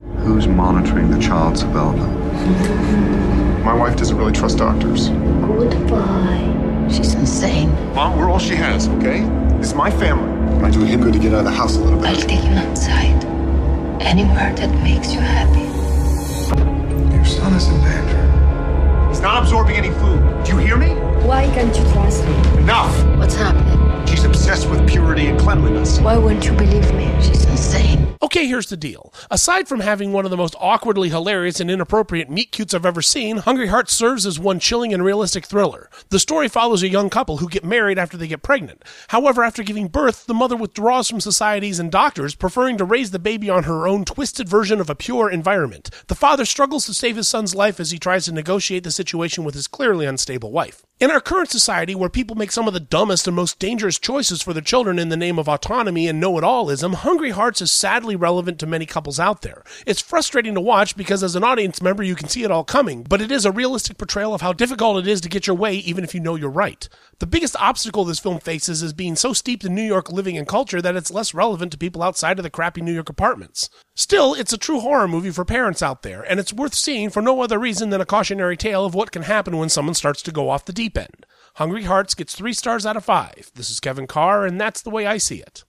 ‘Hungry Hearts’ Movie Review